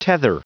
Prononciation du mot tether en anglais (fichier audio)